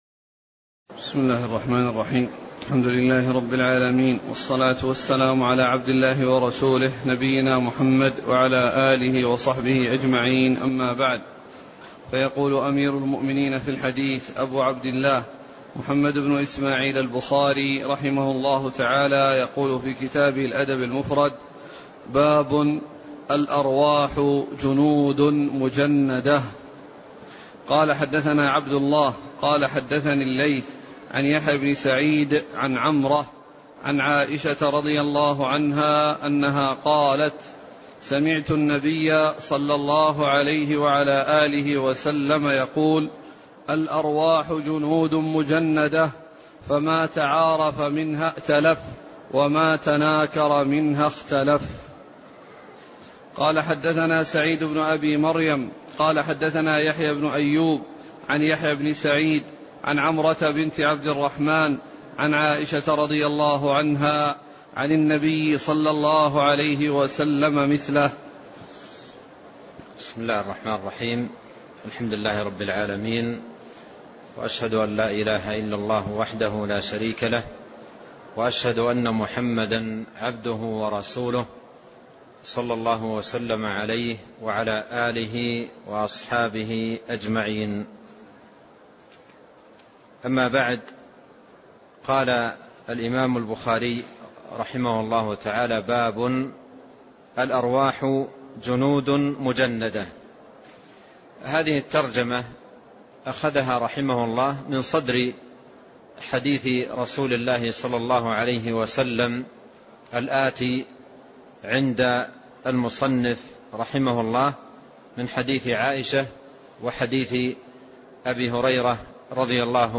شرح الأدب المفرد 119 - باب الأرواح جنود مجندة